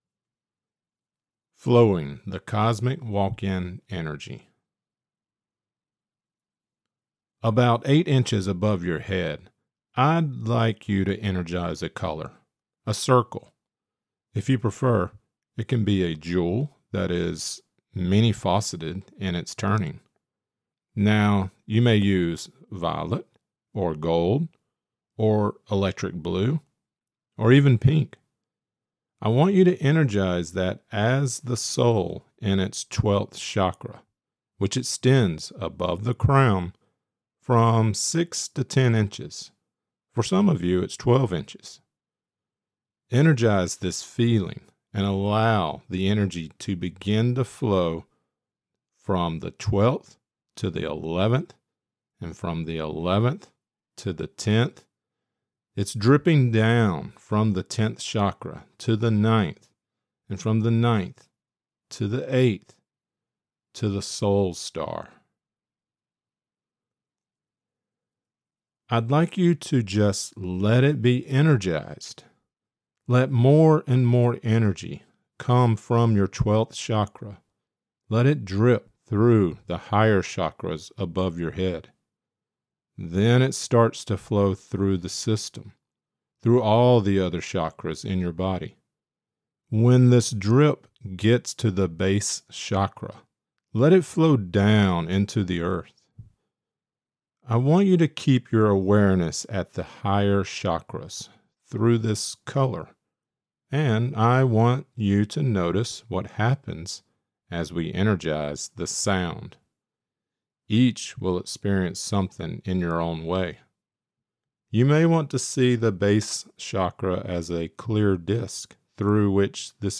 This lecture includes the channeling of Vywamus: * Flowing the Cosmic Walk-in Energy.